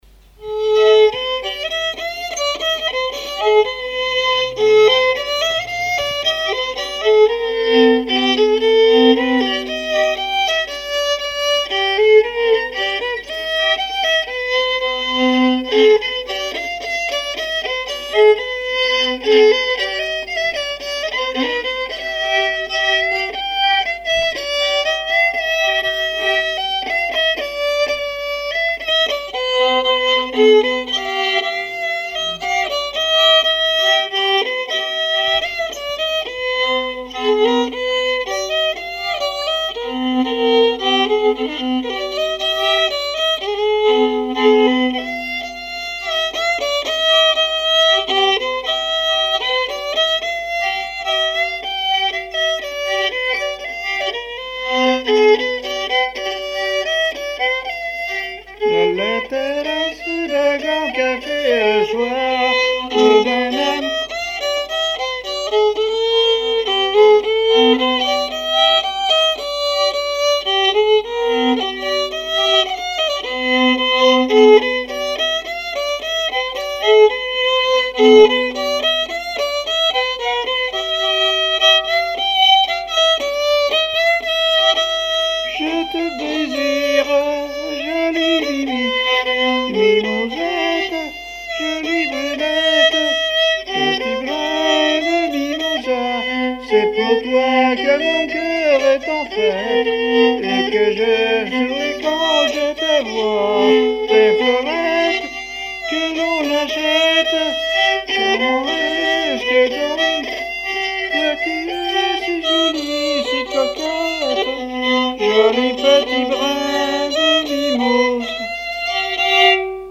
danse : fox-trot
répertoire musical au violon
Pièce musicale inédite